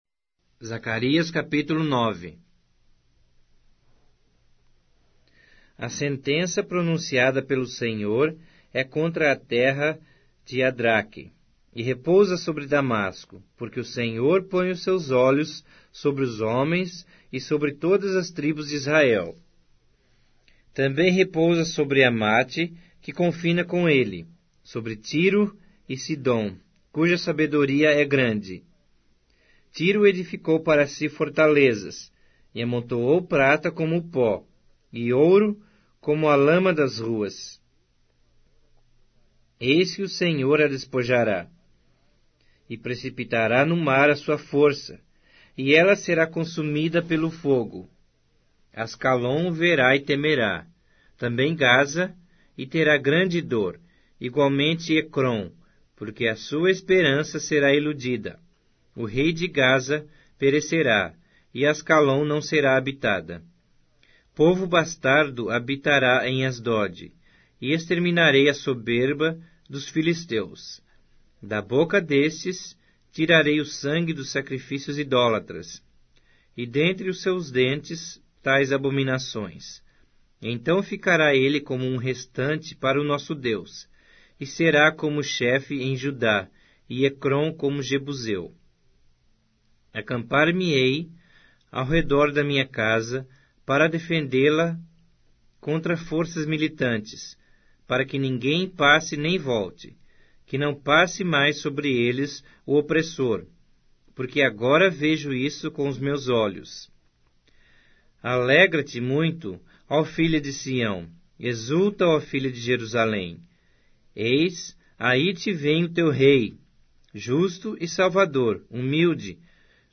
Bíblia Sagrada Online Falada